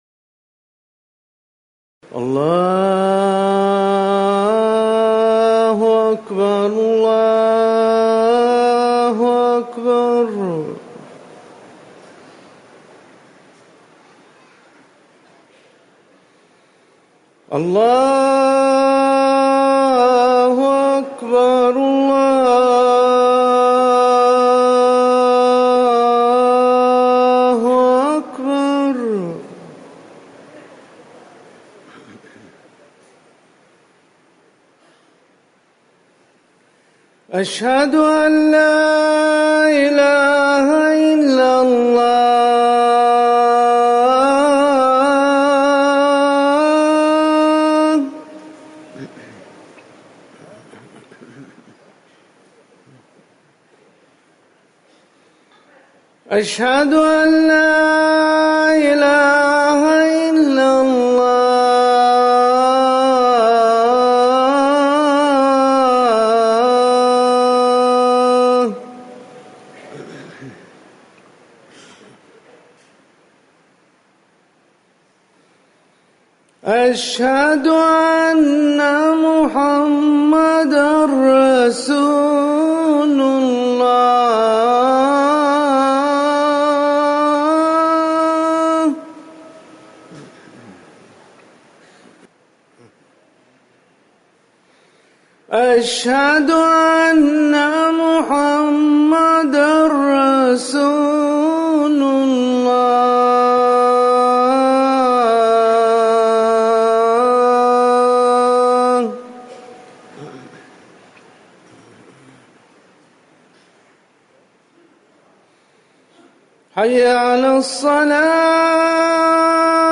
أذان الظهر - الموقع الرسمي لرئاسة الشؤون الدينية بالمسجد النبوي والمسجد الحرام
تاريخ النشر ١٥ محرم ١٤٤١ هـ المكان: المسجد النبوي الشيخ